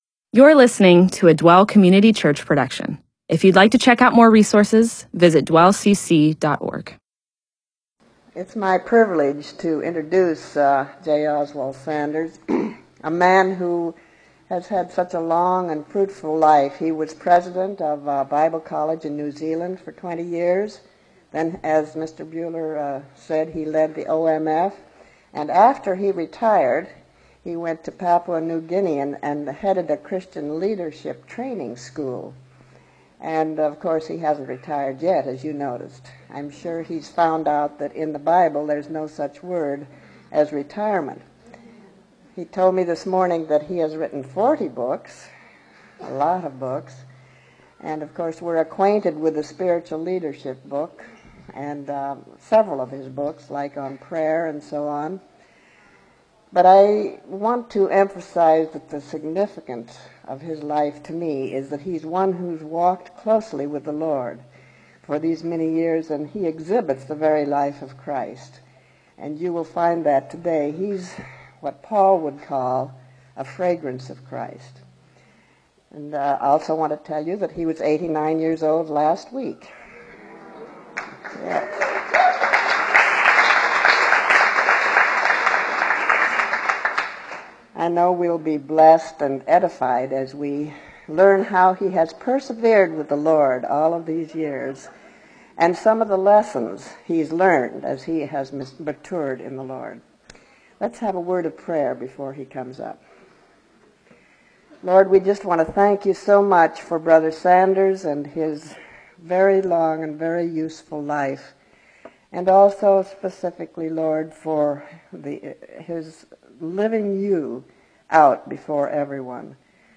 Bible teaching (presentation, sermon) on , Title: Persevering with God, Date: 10/25/1991, Teacher: Oswald Sanders, Includes audio.